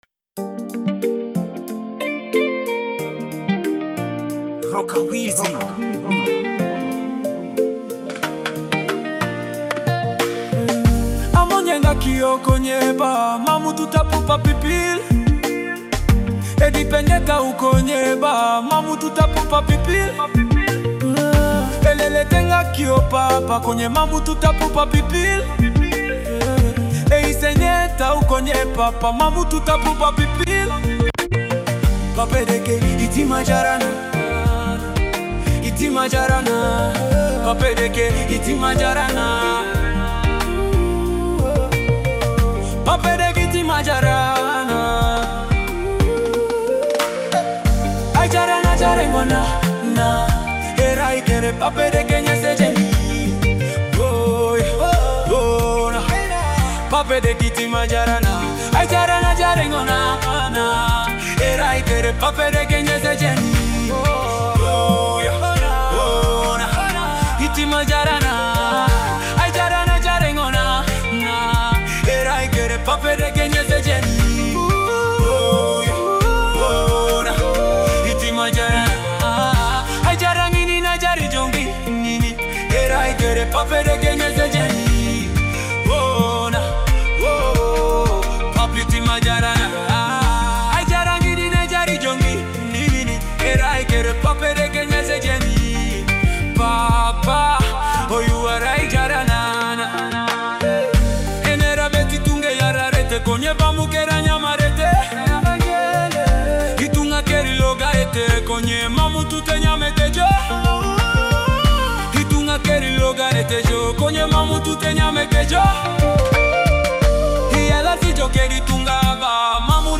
Stream and download this inspiring Ugandan gospel MP3.
deeply emotional and soul-stirring Teso gospel song
With touching lyrics and a moving melody